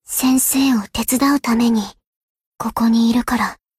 贡献 ） 分类:蔚蓝档案 ； 分类:蔚蓝档案语音 ；协议：Copyright 您不可以覆盖此文件。